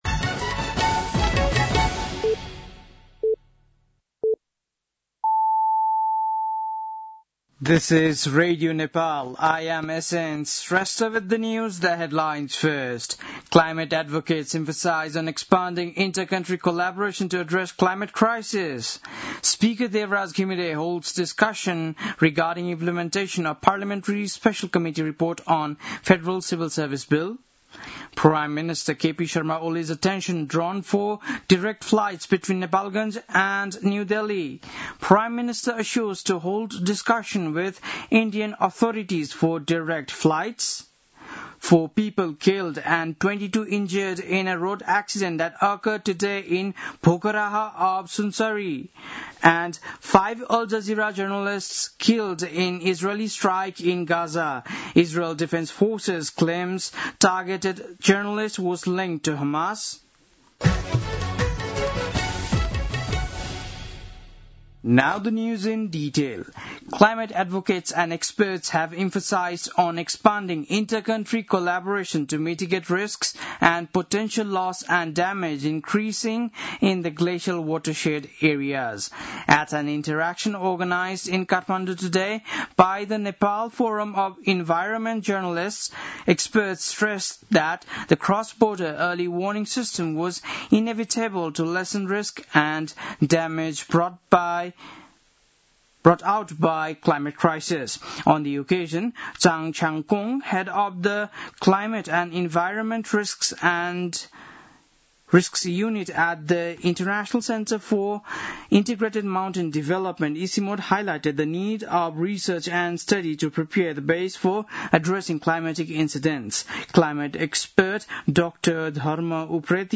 बेलुकी ८ बजेको अङ्ग्रेजी समाचार : २६ साउन , २०८२
8-pm-english-news-4-26.mp3